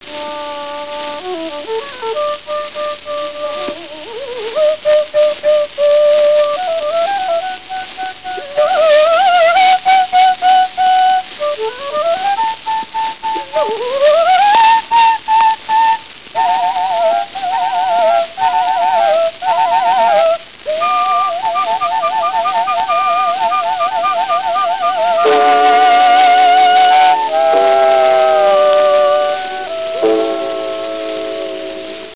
Extrait de l'aria
chanté par Emma Albani. (IRCC, ©1904)